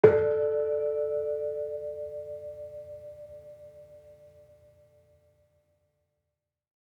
Gamelan Sound Bank
Kenong-resonant-A3-f.wav